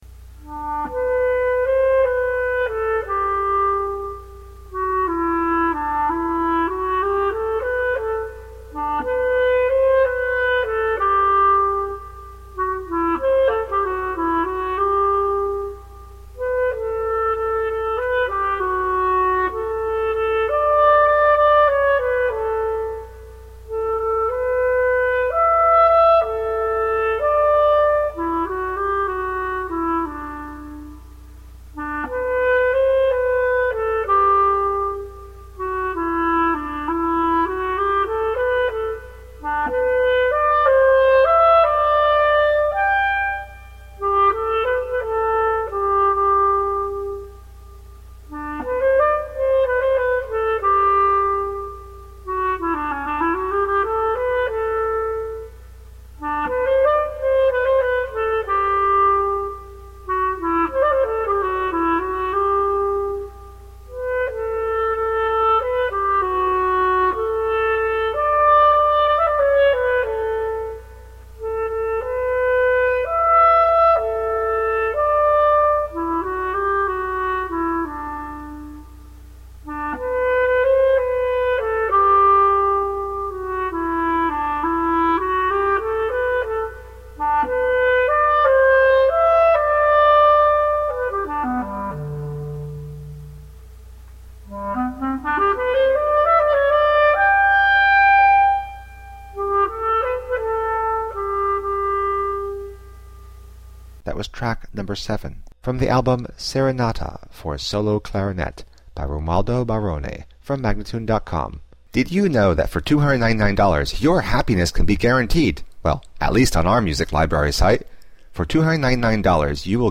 Classical solo clarinet.